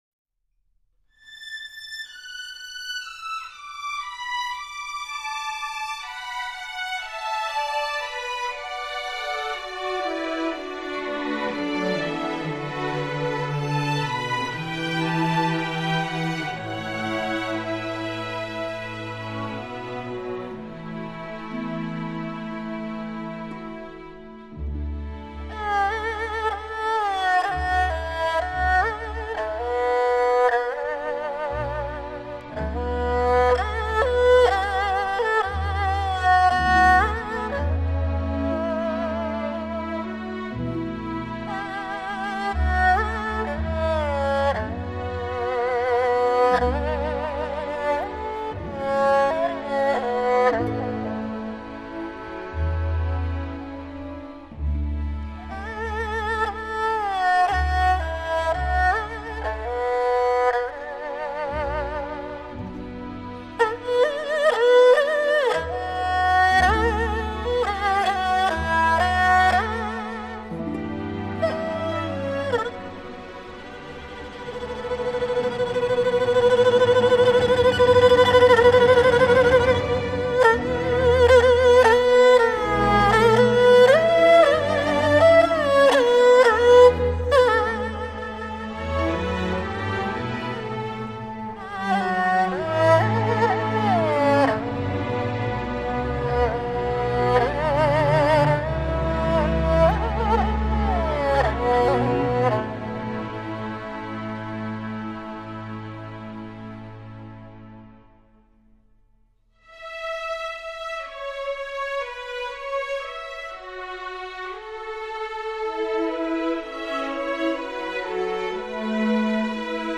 小提琴
低音二胡